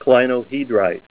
Help on Name Pronunciation: Name Pronunciation: Clinohedrite + Pronunciation
Say CLINOHEDRITE Help on Synonym: Synonym: ICSD 200432   PDF 17-214